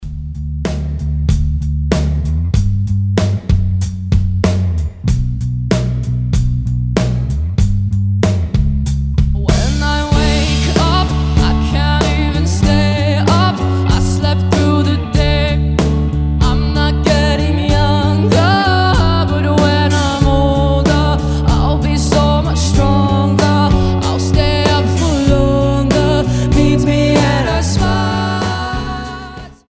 • Качество: 320, Stereo
женский голос
alternative